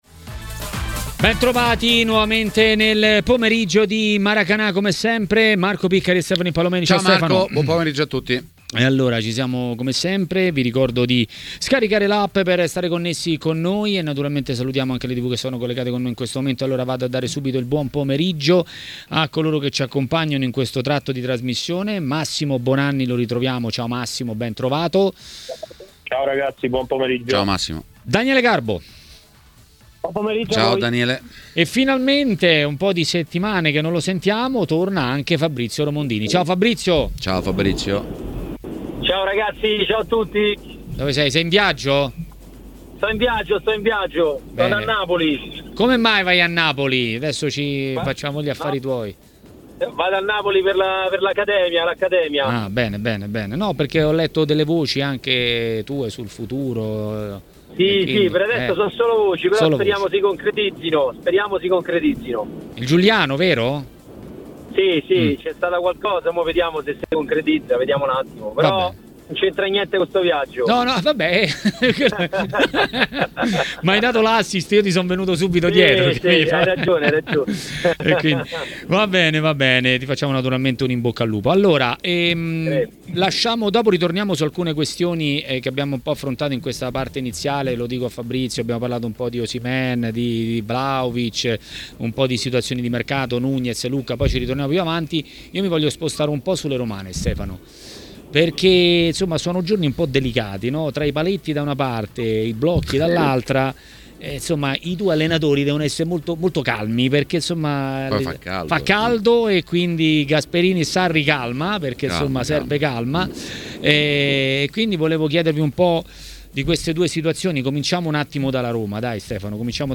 A intervenire a TMW Radio, durante Maracanà, è stato l'ex calciatore